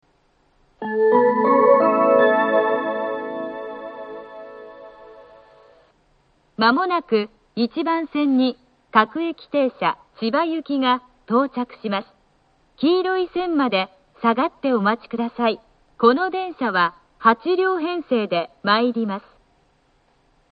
１番線接近放送 各駅停車千葉行（８両）の放送です。